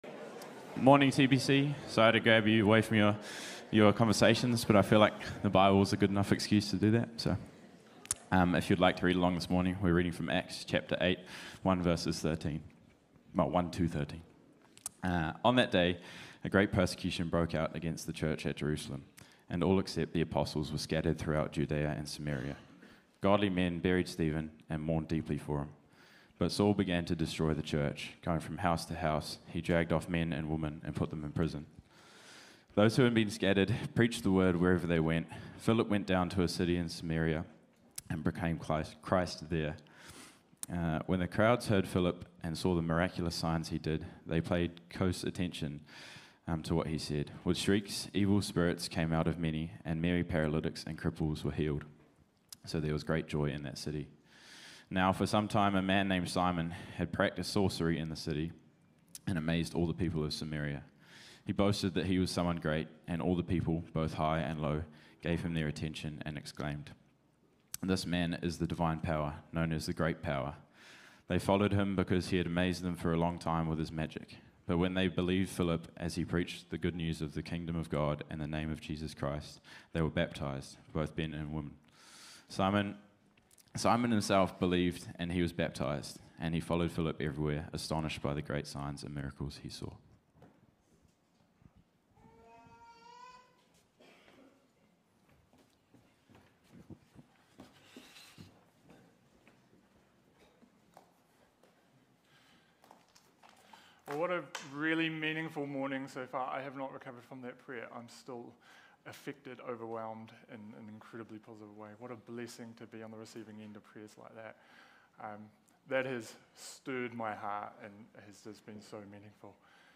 Sermons | Titirangi Baptist Church